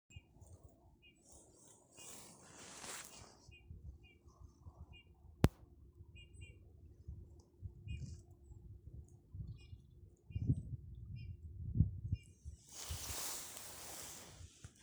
снегирь, Pyrrhula pyrrhula
Ziņotāja saglabāts vietas nosaukumsBauskas nov, Valle
СтатусСлышен голос, крики